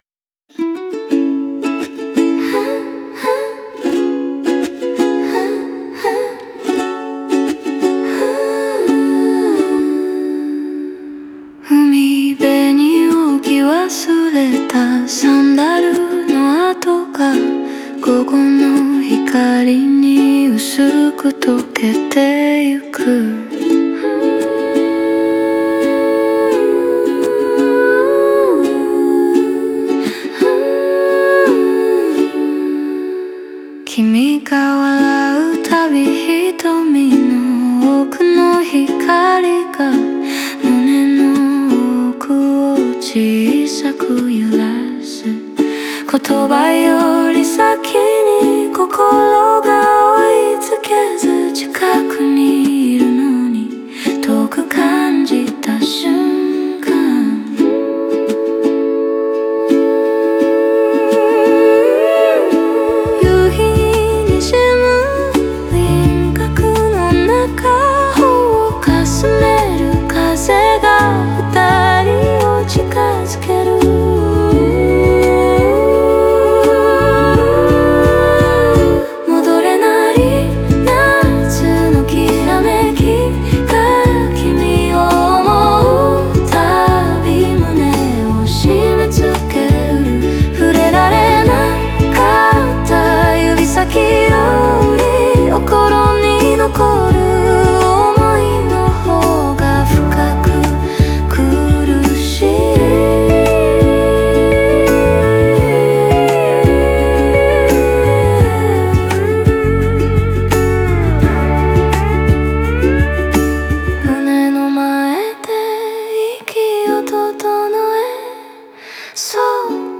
オリジナル曲♪
全体を通して、成熟した恋の切なさと、後に残る余韻を静かに響かせる構成になっています。